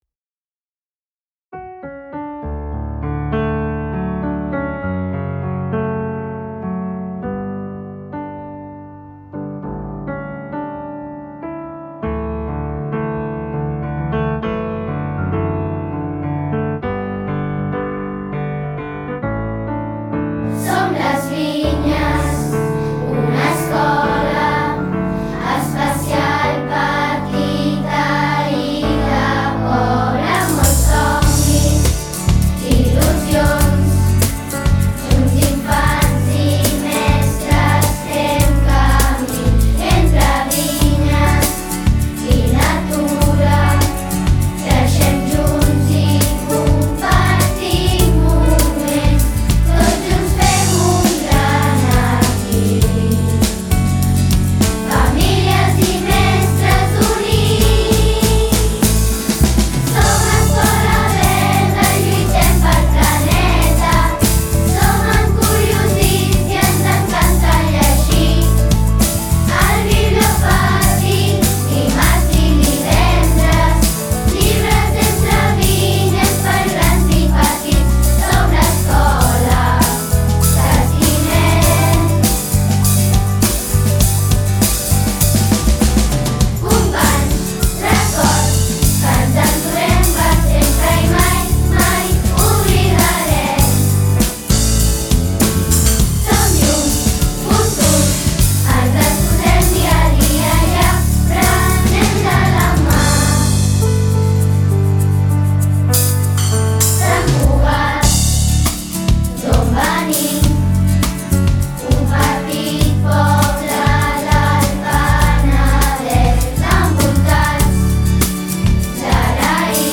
Hem pogut gaudir de l’experiència d’enregistrar-la en un estudi de gravació professional i fins i tot ser els creadors de la portada d’aquesta.
• Som Les Vinyes (alumnes)